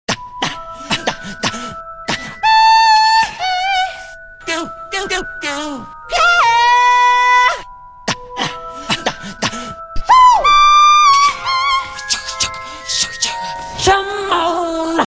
Funny